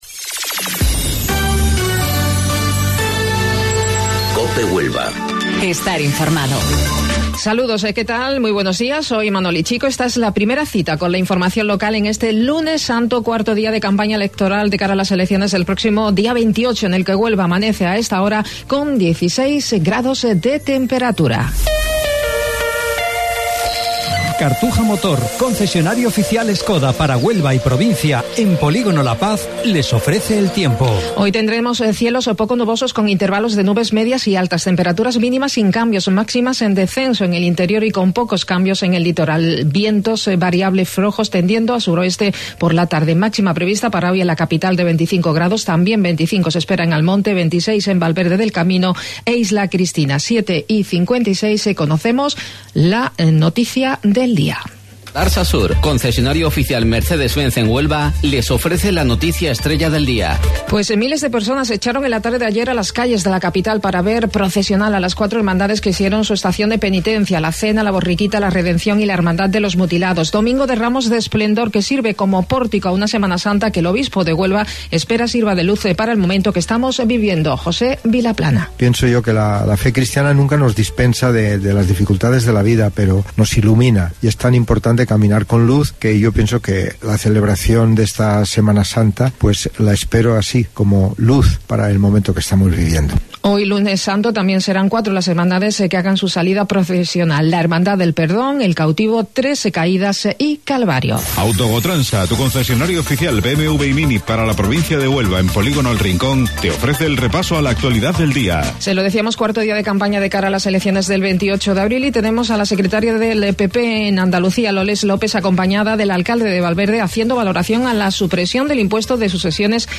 AUDIO: Informativo Local 07:55 del 15 de Abril